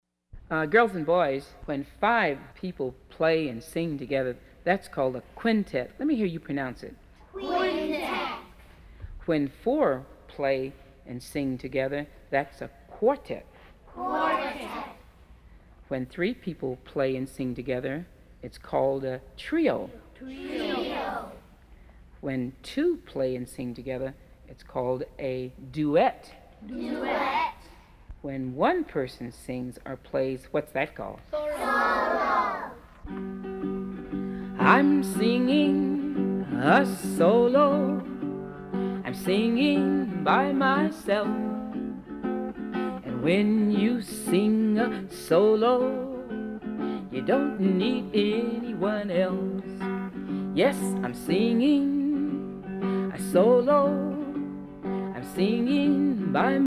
Listen to the varying TONES of these four children: